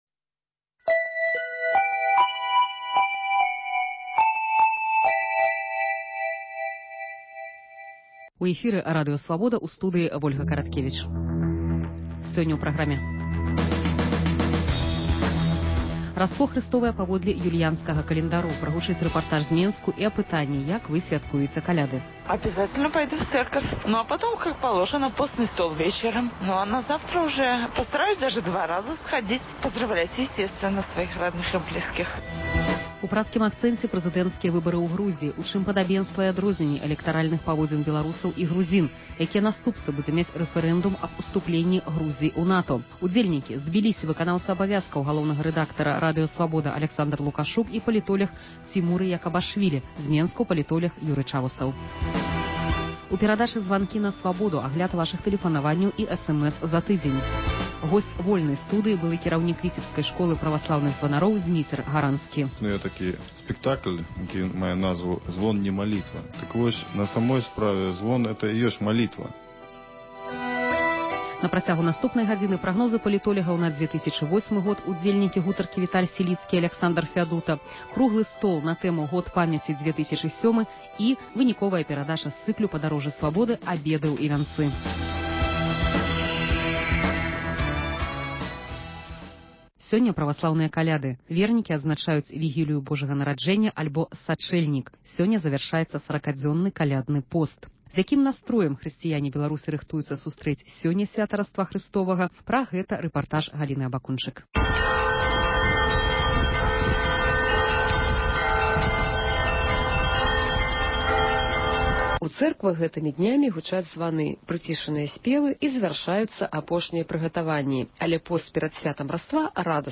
Праскі акцэнт. Званкі на Свабоду. Вольная студыя.